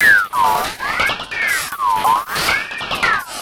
E Kit 12.wav